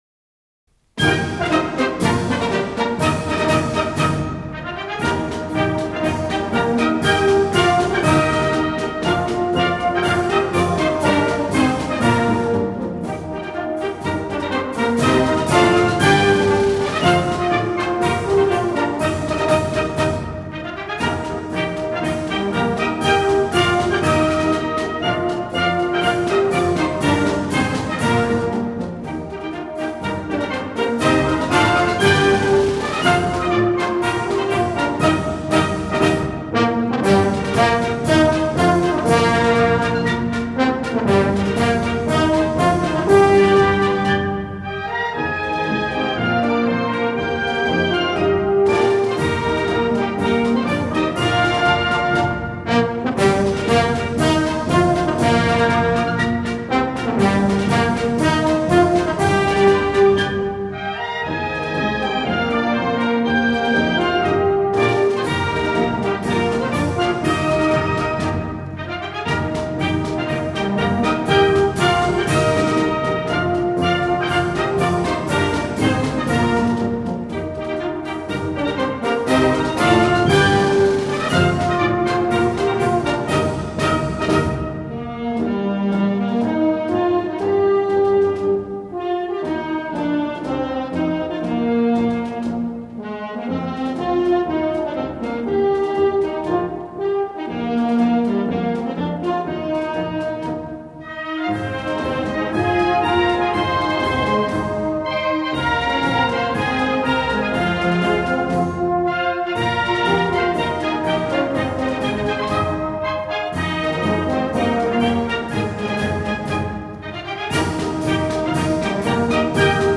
欢快乐观的生活态度以及团结奋进的向上精神，旋律流畅，感染力强，